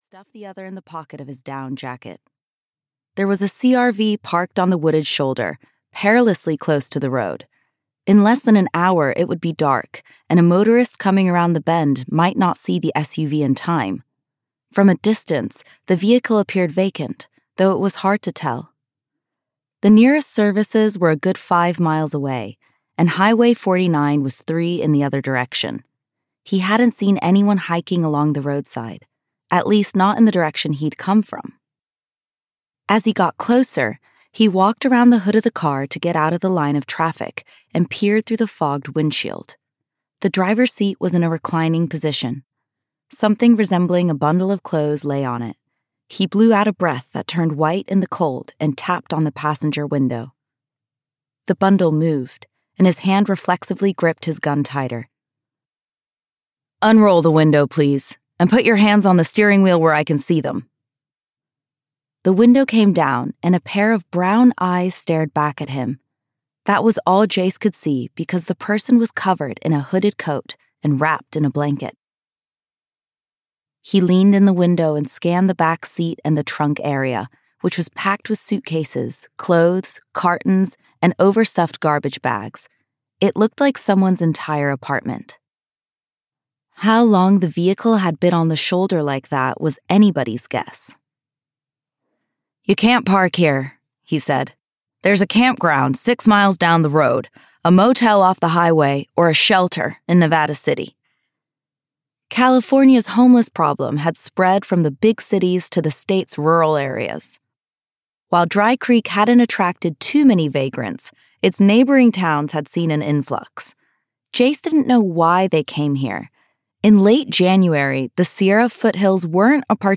Audio knihaCowboy Tough: A smalltown, single dad rancher romance (EN)
Ukázka z knihy